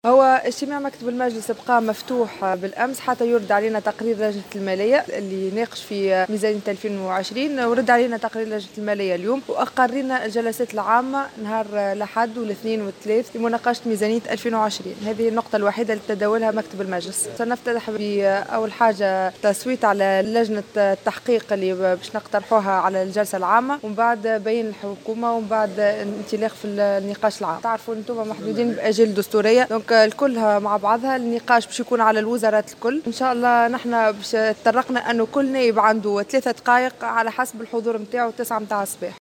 وفي تصريح للجوهرة أف أم أكدت عضو مكتب مجلس نواب الشعب نسرين العماري أن الجلسة العامة ستنطلقُ ببيان للحكومة الحالية ثم مداخلات النواب ،على أن تتواصل الجلسات العامة أيام الأحد والإثنين والثلاثاء.